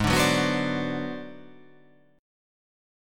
G#7#9b5 Chord